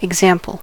example: Wikimedia Commons US English Pronunciations
En-us-example.WAV